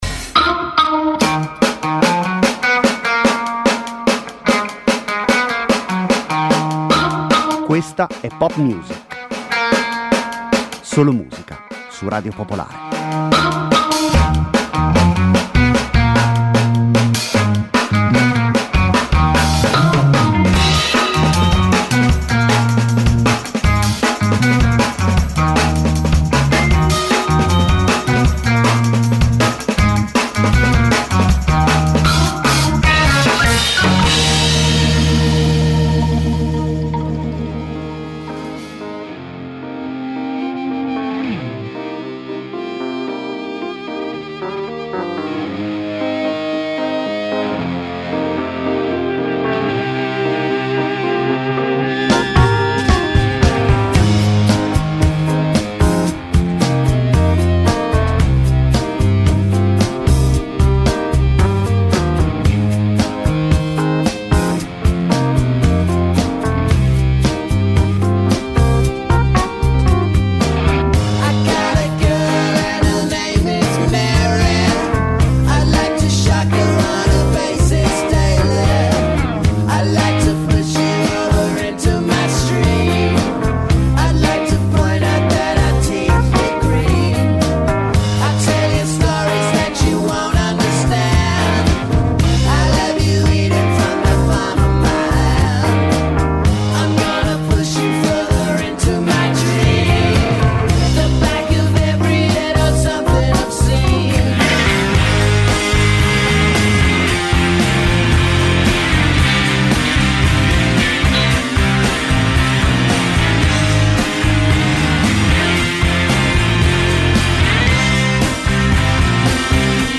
Una trasmissione di musica, senza confini e senza barriere.
Senza conduttori, senza didascalie: solo e soltanto musica.